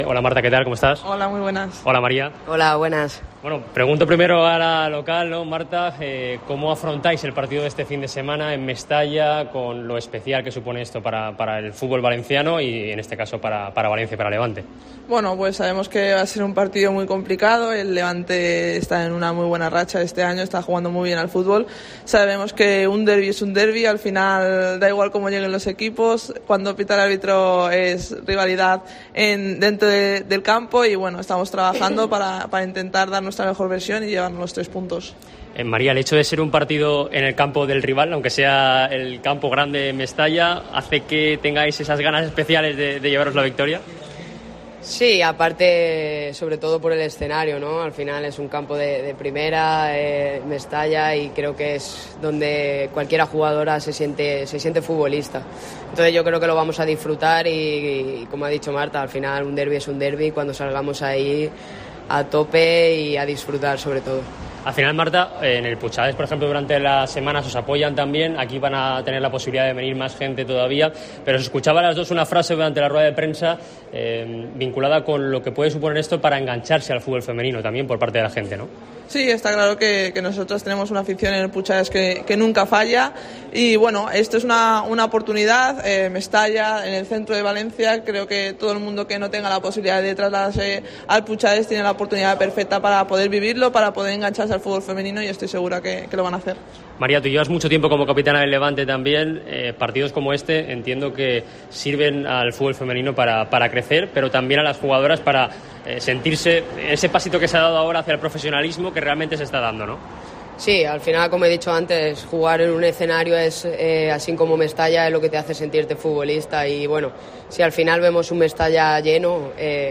DERBI TEIKA Entrevista